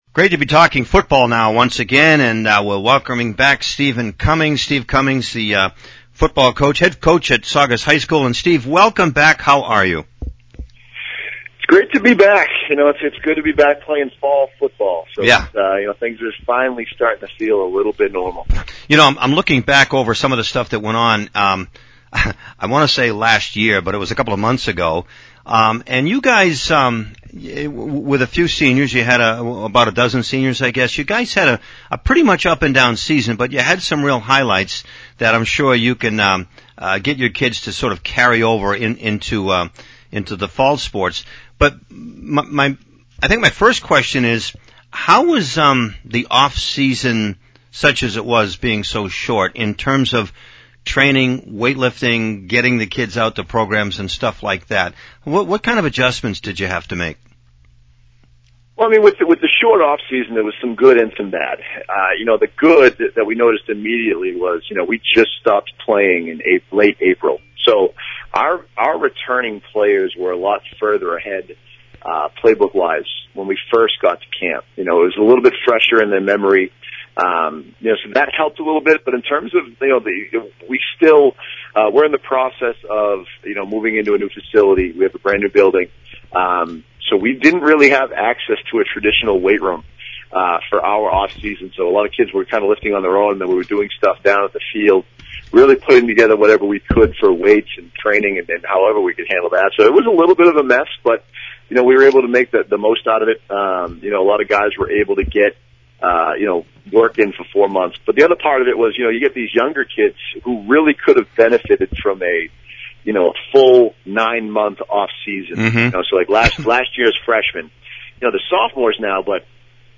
High School Football Pregame Talk